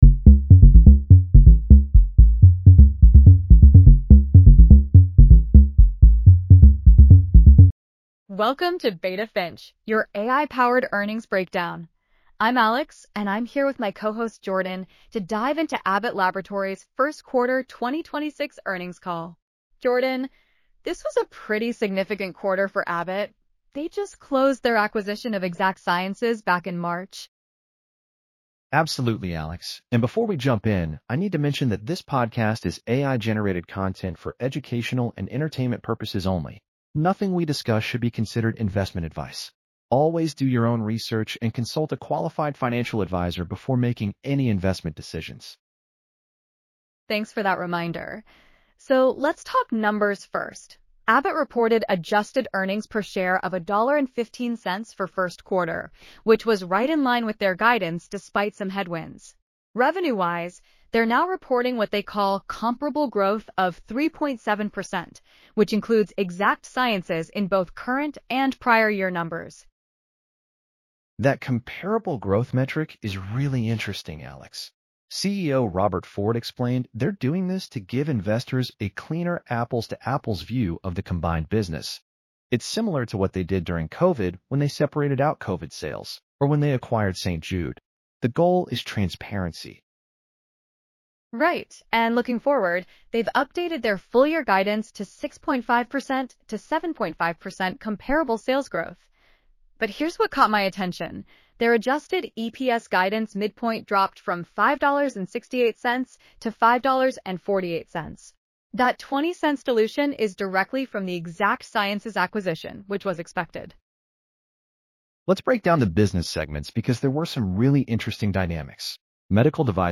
This is AI-generated content for educational purposes only.